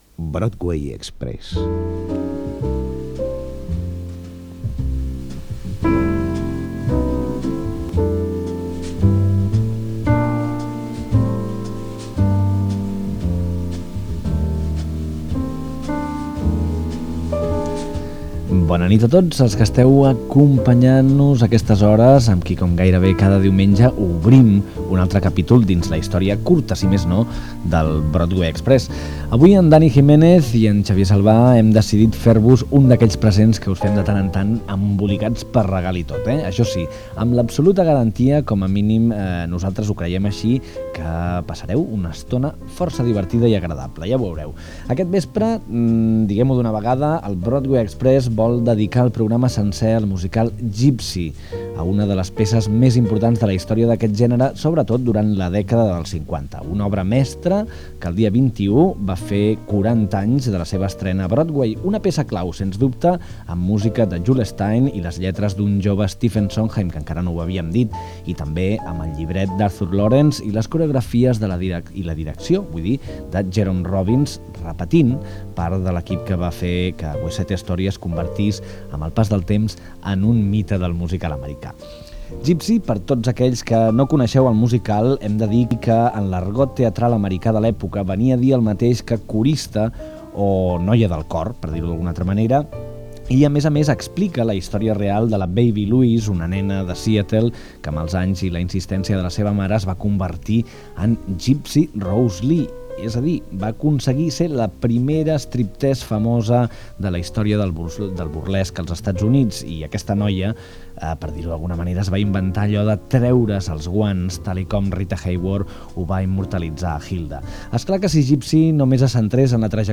Musical
FM
Fragment extret de la bobina magnètica que es va fer servir per a l'emissió del programa.